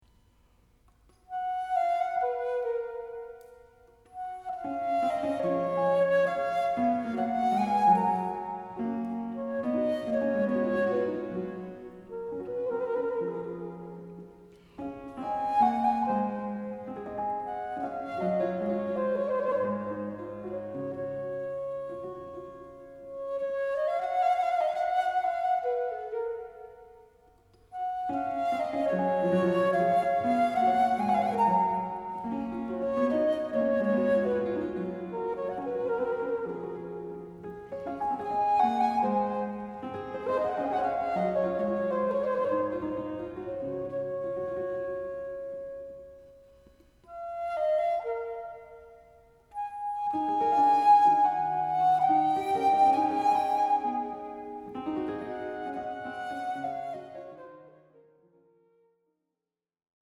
E minor, for traverse and basso continuo